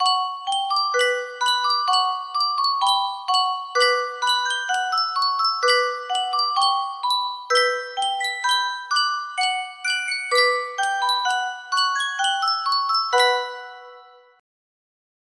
Sankyo Music Box - 黒田節 BW
Full range 60